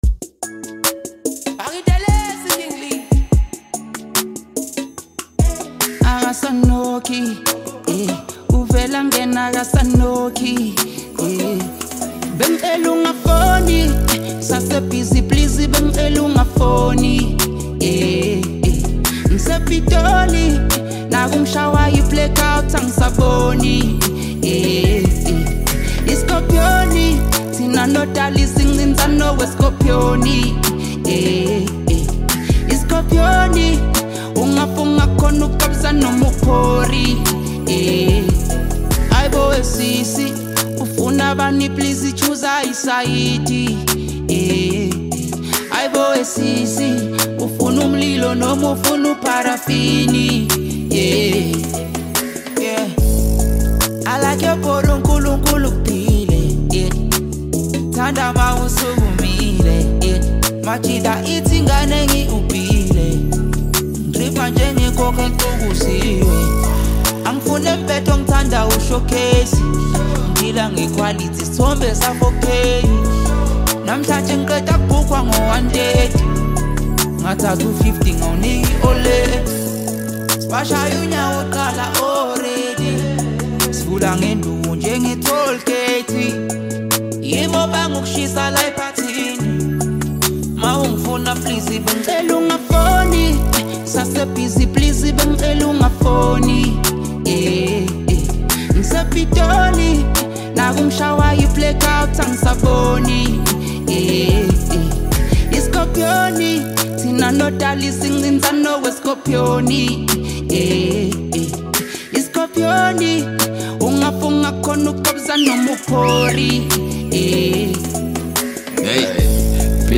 Home » Amapiano » Hip Hop » Latest Mix